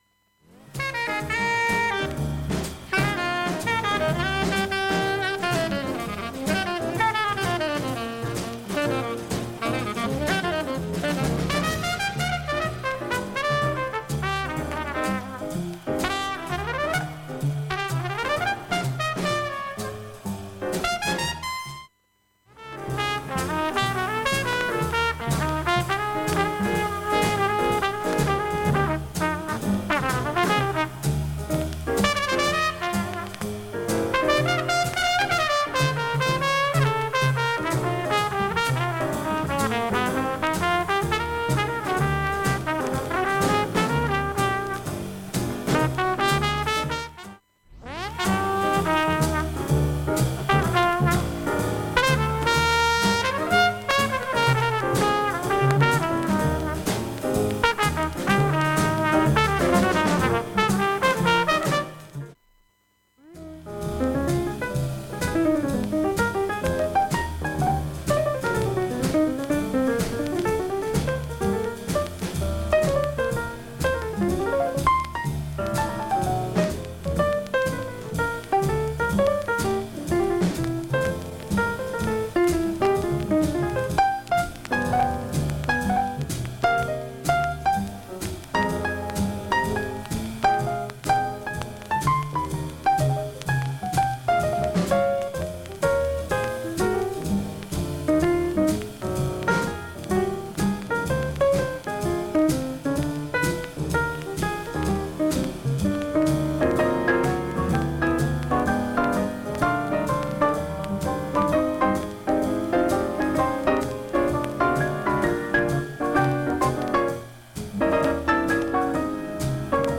わずかなプツプツ出ます。
B-1後半1分半ほどチリプツ多めの箇所あります。
現物の試聴（上記録音時間３分半）できます。音質目安にどうぞ